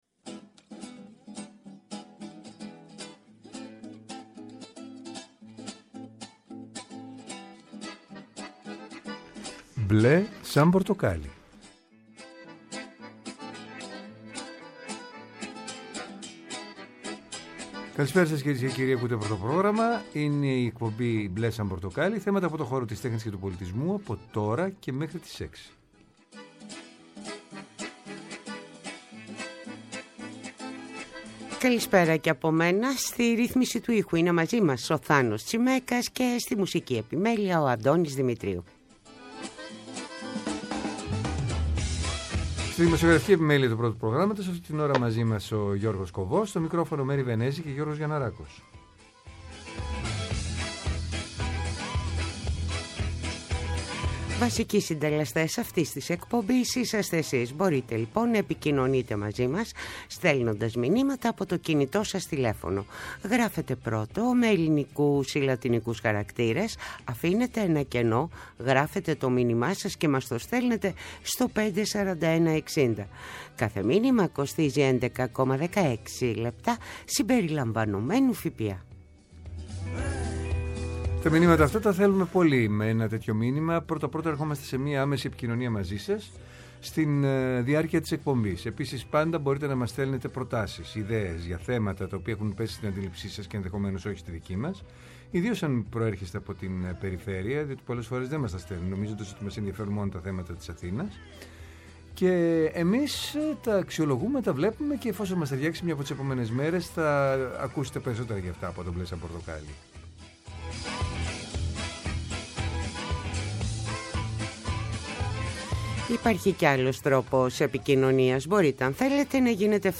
Τηλεφωνικά καλεσμένοι μας είναι: